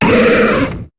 Cri de Grotadmorv dans Pokémon HOME.